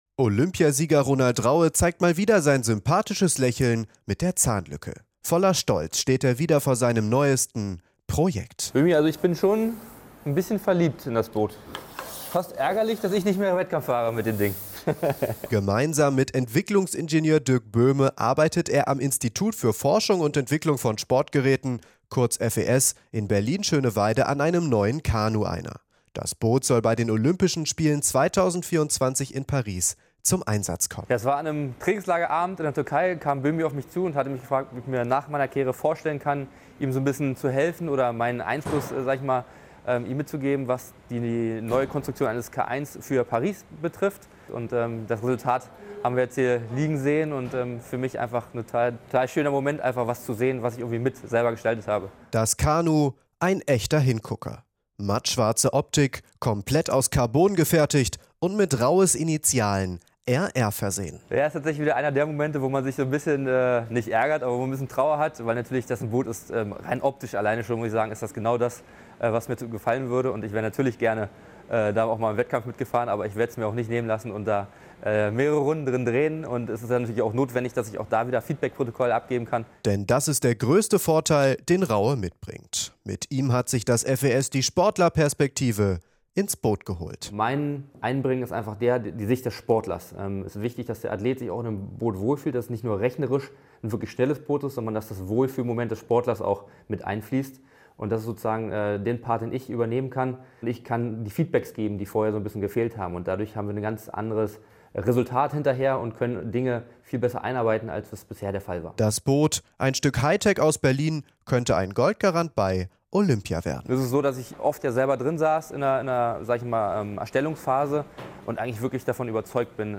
Inforadio Nachrichten, 17.04.2023, 10:00 Uhr - 17.04.2023